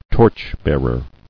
[torch·bear·er]